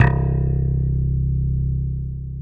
KW FUNK  C 1.wav